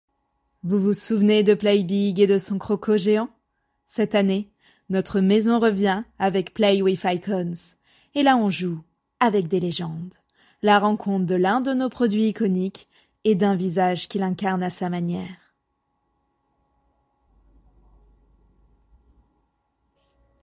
Essais voix 2
5 - 37 ans - Mezzo-soprano